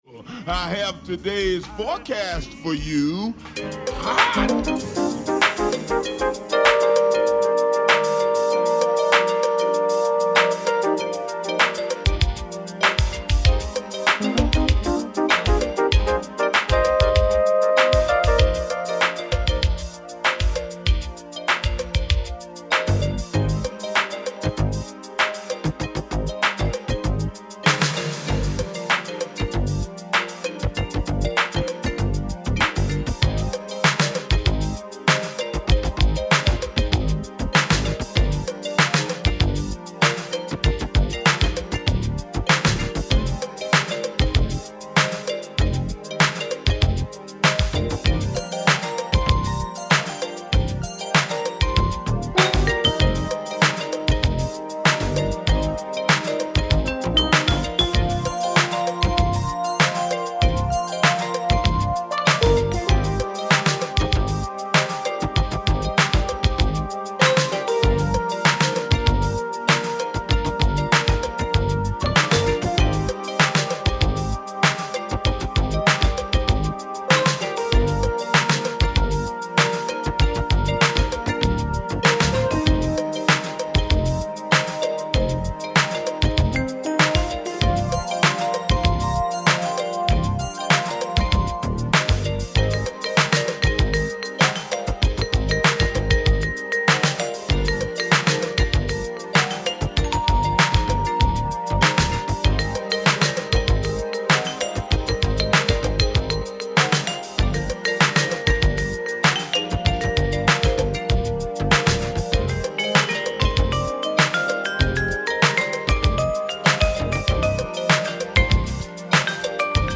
清涼感さながらのSoul, Jazz Funkを皮切りに、クラシックHip Hopを織り交ぜ
〈試聴〉ダイジェストになります。